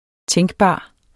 Udtale [ ˈtεŋgˌbɑˀ ]